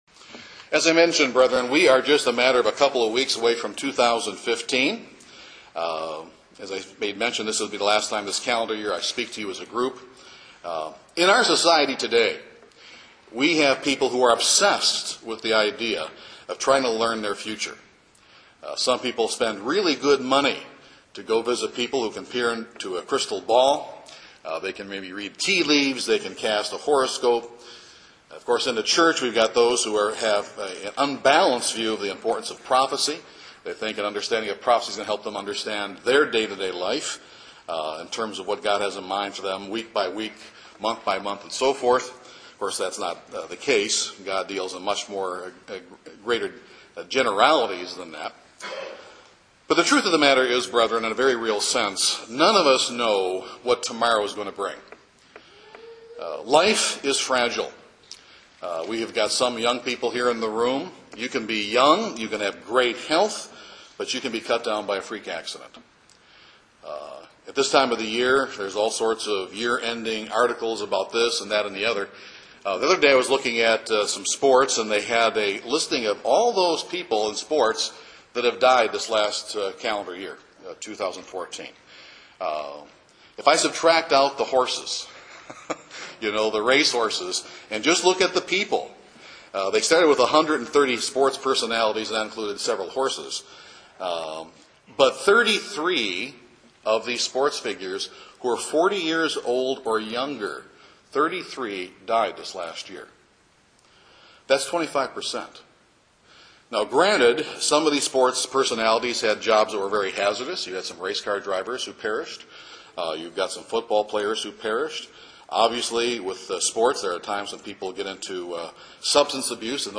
How do we face these challenges and is there anything we can do to prepare for them in advance? This sermon speaks to those issues.